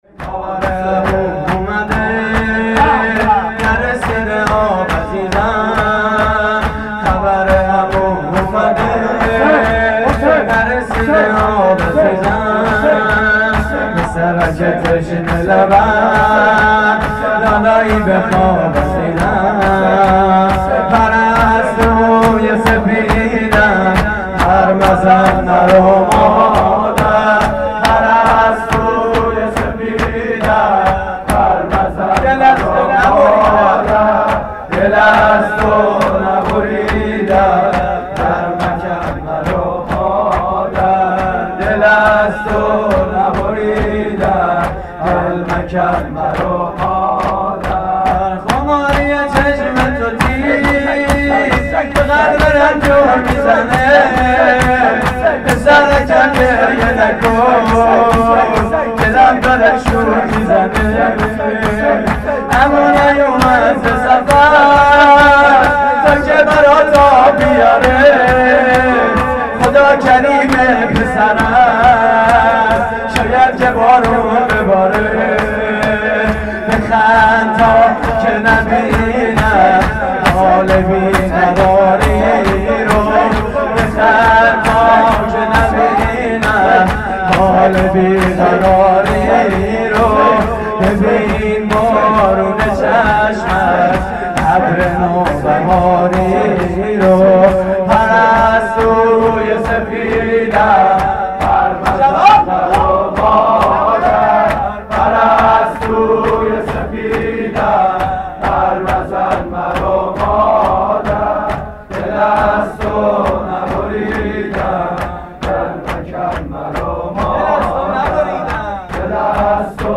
شور: خبر عمو اومده
مراسم عزاداری شب هفتم محرم 1432